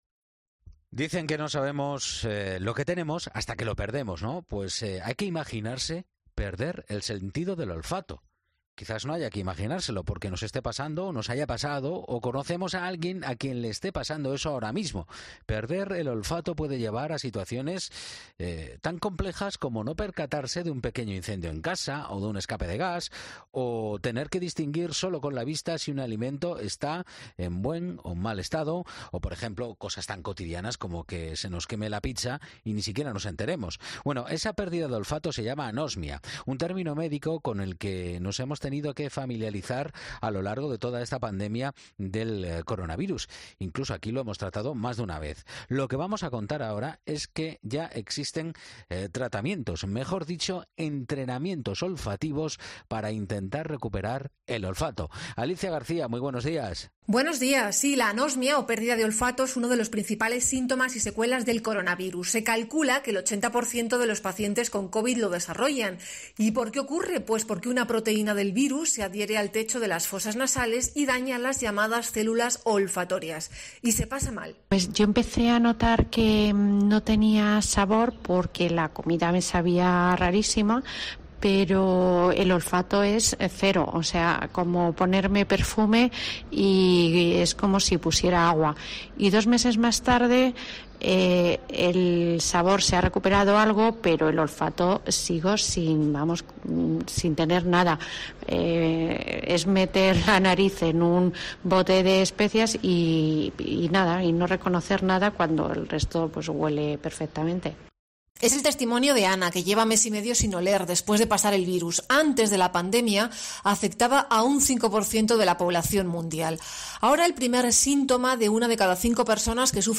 Expertos de la Clínica Universidad de Navarra nos explican en qué consiste